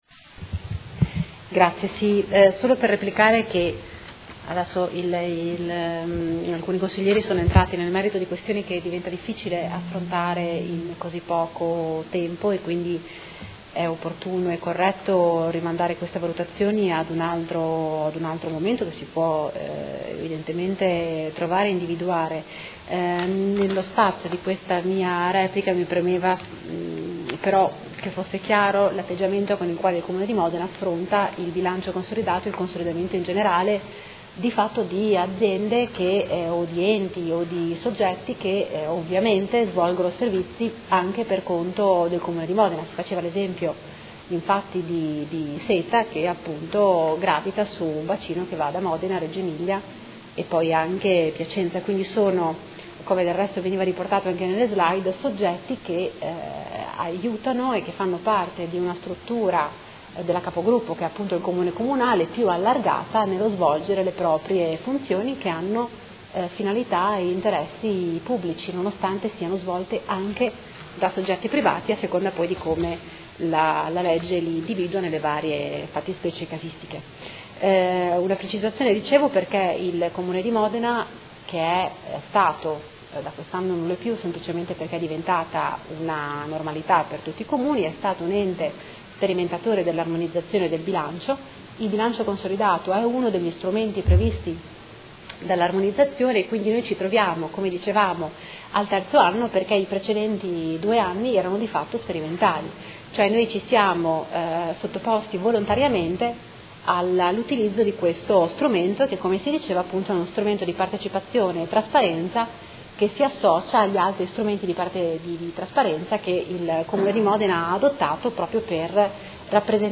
Seduta del 22/09/2016 Proposta di deliberazione: Bilancio Consolidato 2015 del Gruppo Comune di Modena, verifica finale del controllo sulle Società partecipate per l’esercizio 2015 e monitoraggio infrannuale 2016. Replica